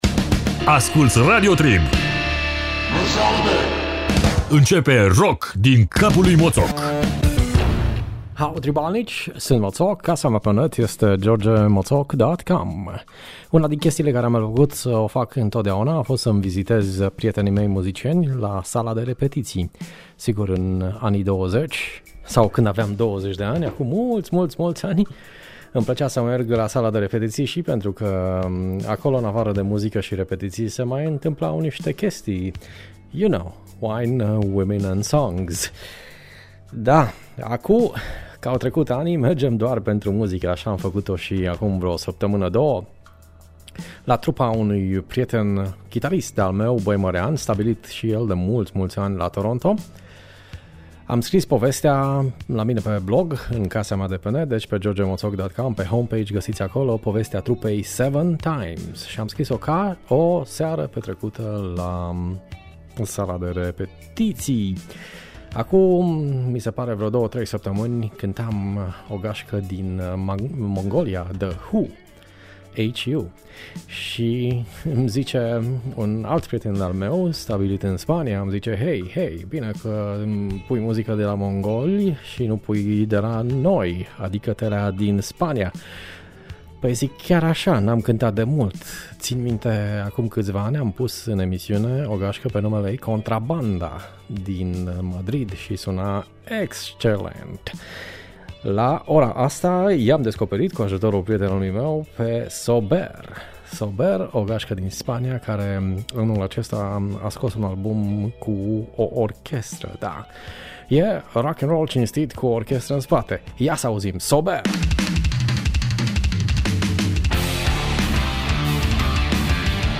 Furia rock suna bine si in spaniola.